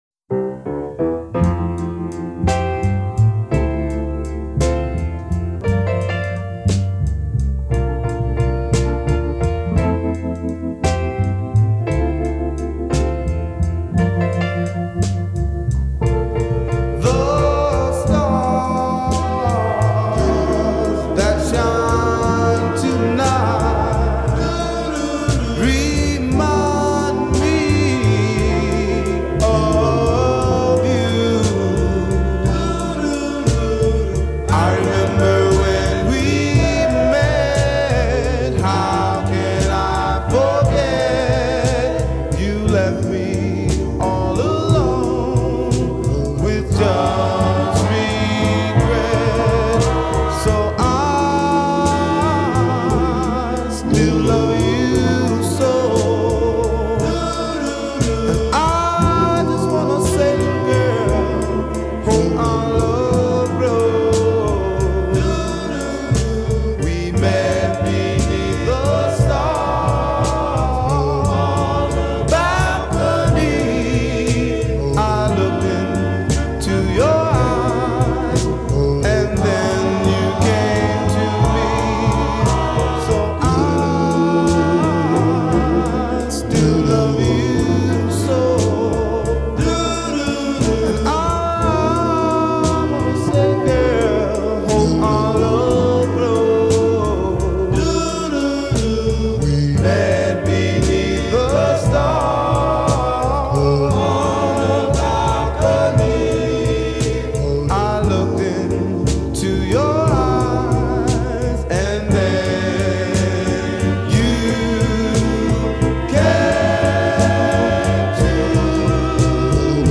Tag: Doo Wop